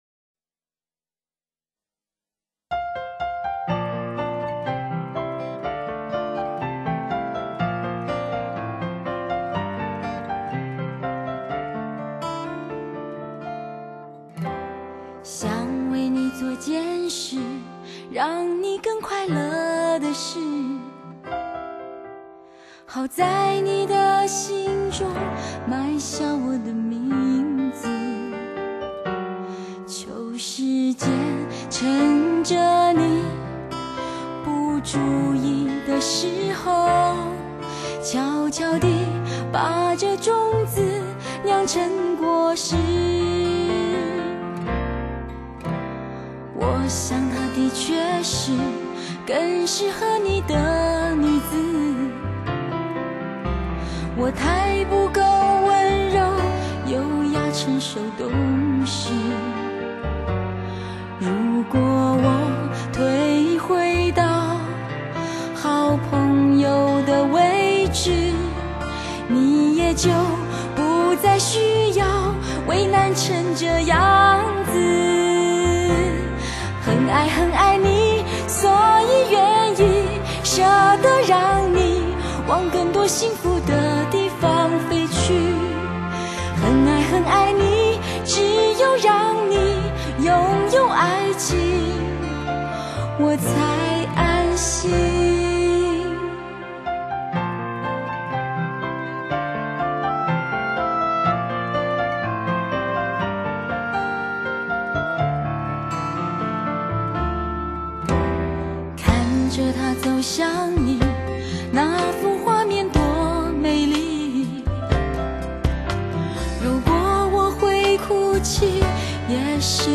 但她素净的歌声，却唱尽知性女人爱情中千般滋味……